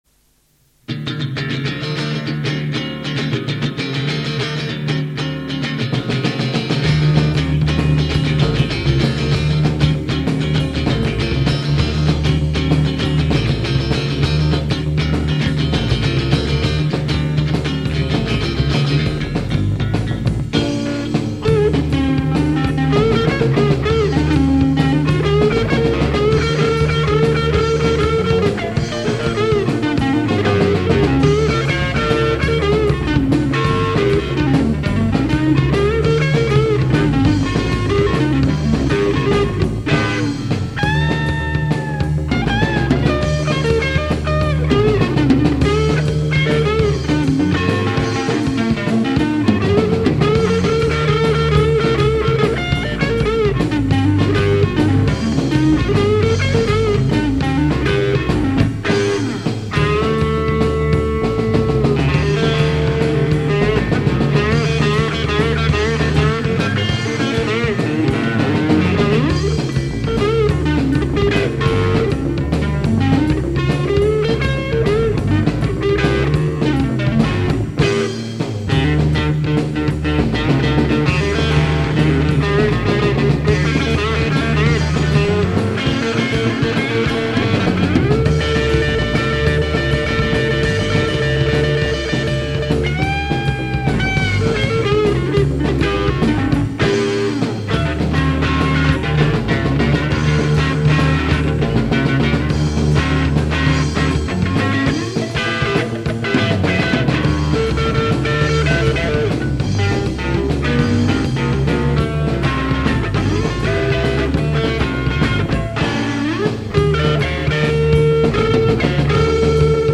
A traditional blues instrumental.